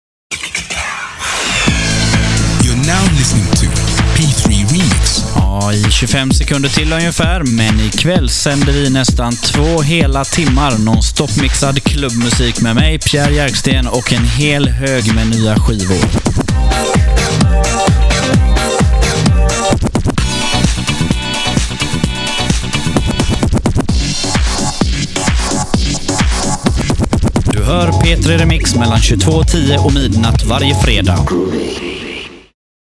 Här nedan hör du lite reklam för programmen.